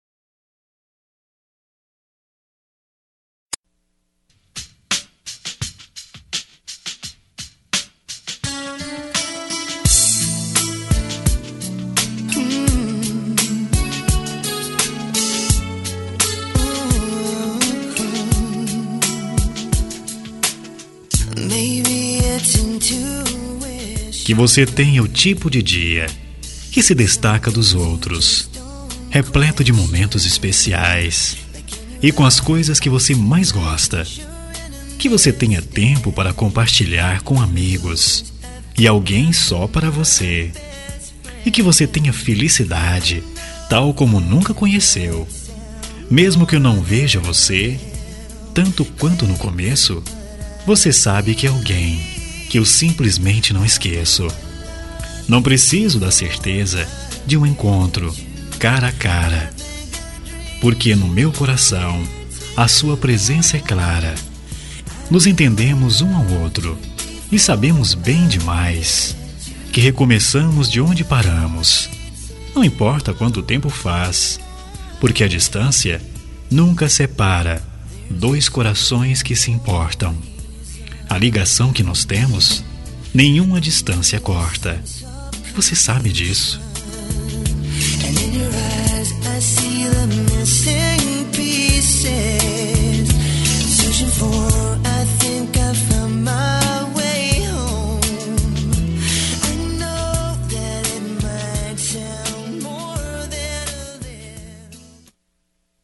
Telemensagem de Aniversário de Ex. – Voz Masculina – Cód: 1377
Telemensagem Aniversário Ex- Masc- 1117.mp3